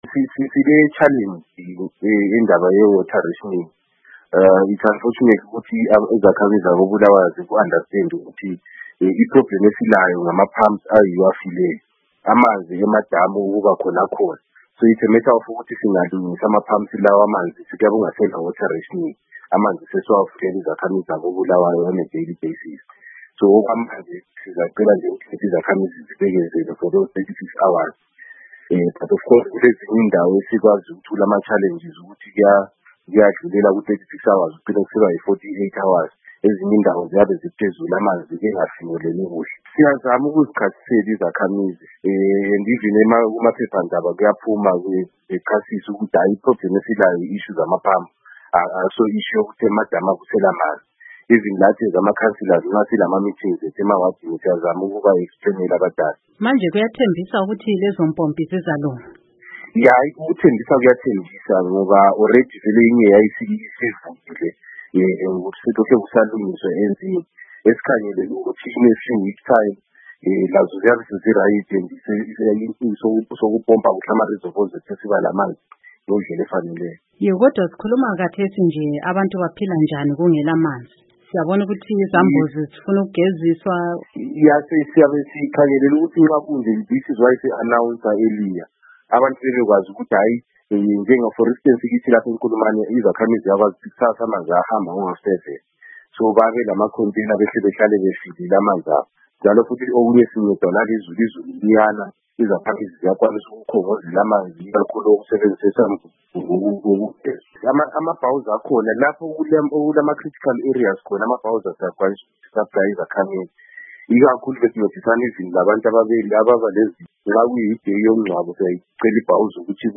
Ingxoxo loKhansila Rodney Jele